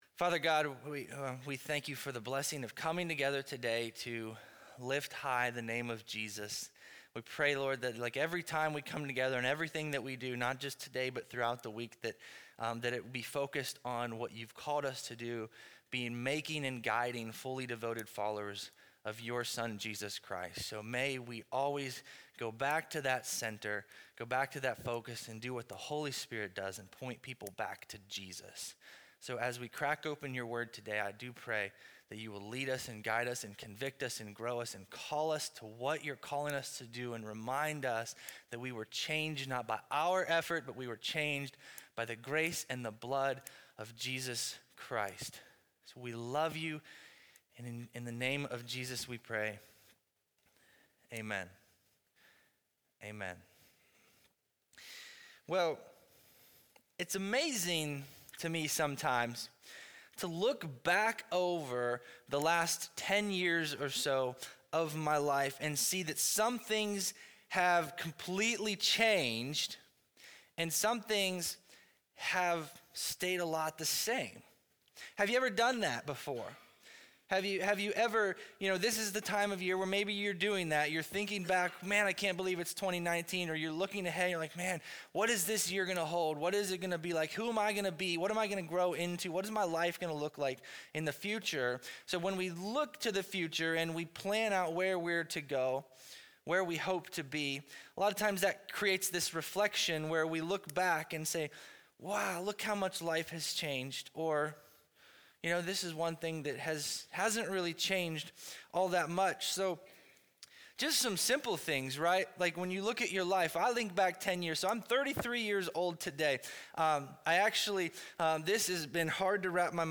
2019 Changed Preacher